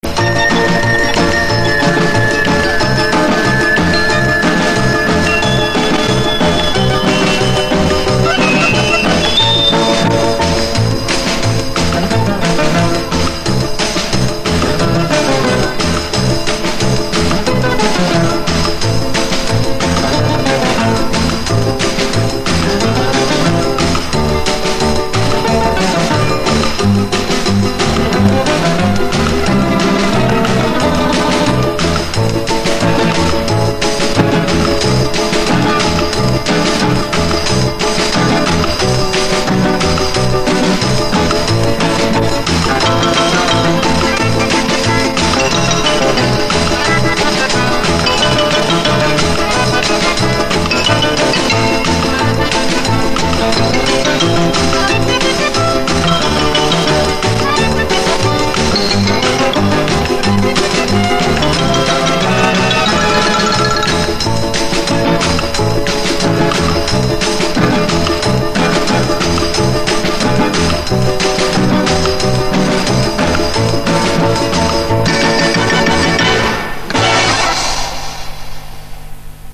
К сожалению записана не с начала, так как плёнка порвалась